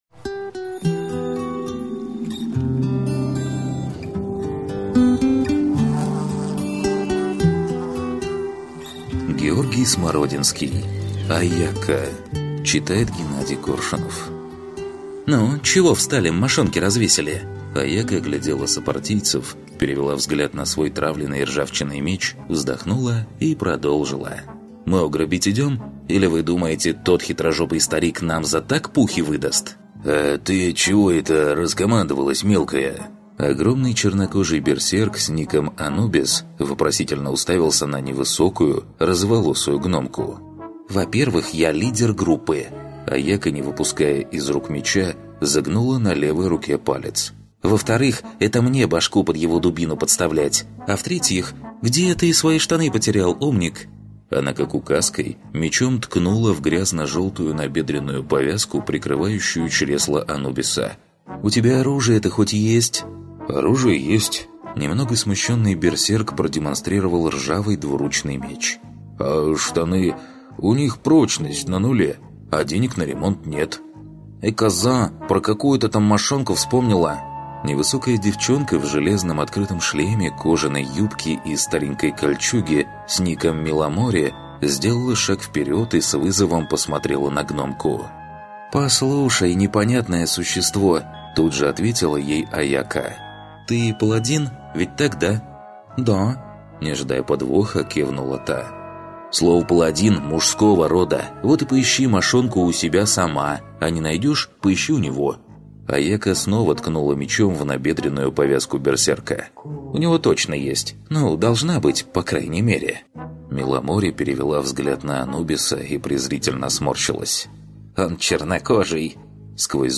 Аудиокнига Аяка | Библиотека аудиокниг